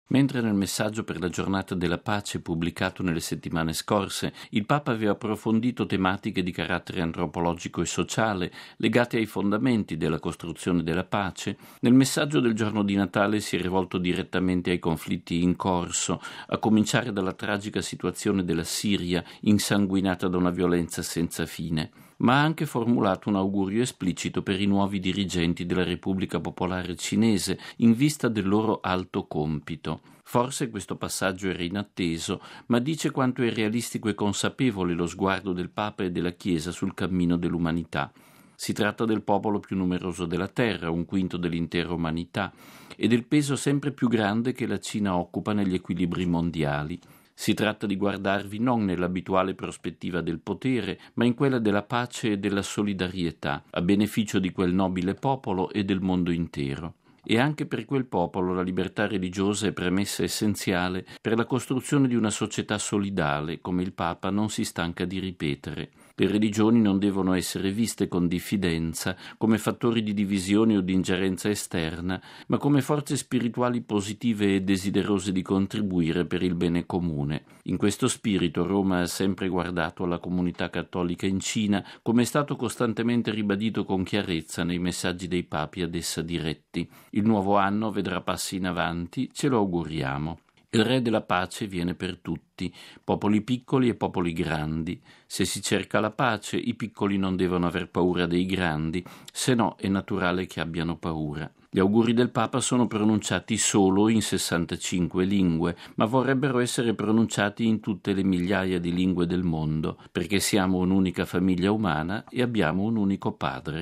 Auguri cinesi: editoriale di padre Lombardi
Così, nel Messaggio natalizio Benedetto XVI è tornato a parlare della realtà cinese. Ascoltiamo in proposito il nostro direttore, padre Federico Lombardi, nel suo editoriale per Octava Dies, il Settimanale informativo del Centro Televisivo Vaticano:RealAudio